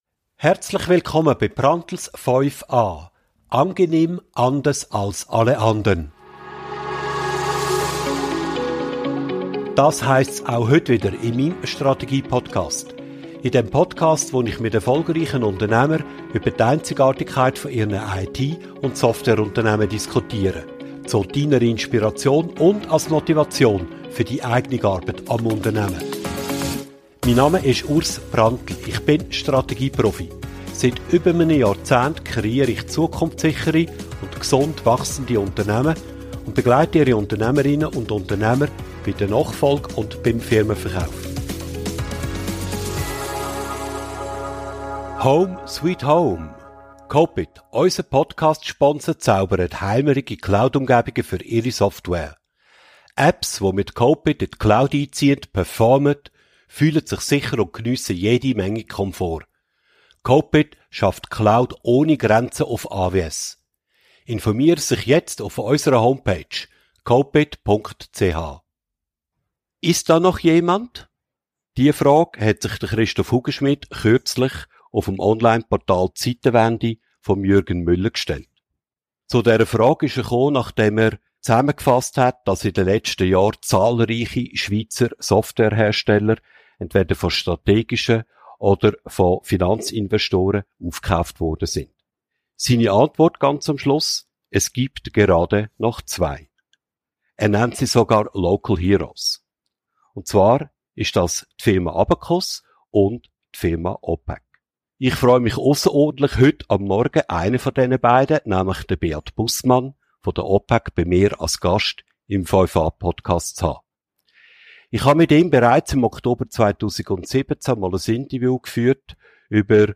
Opacc – Besseres für Wenige | Im Gespräch